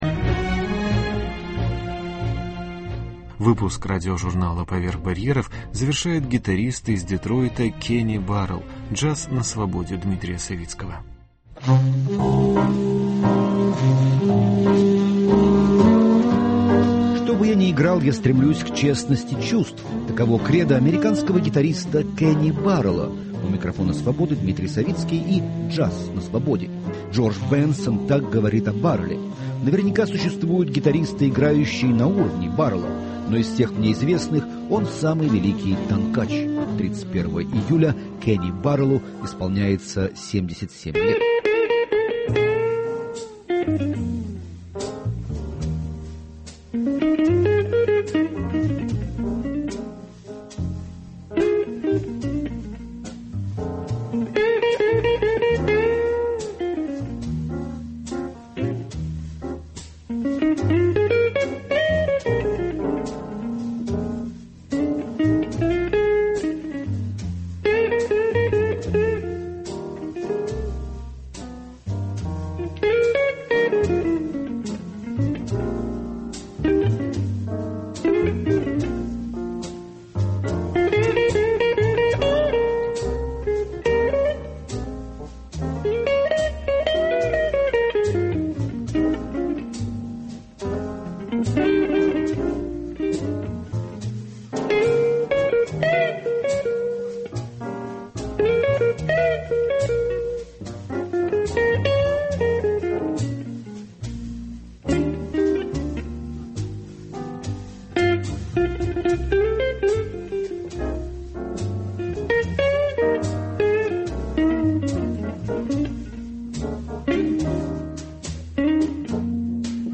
Джаз на свободе Дмитрия Савицкого : гитарист Кенни Барелл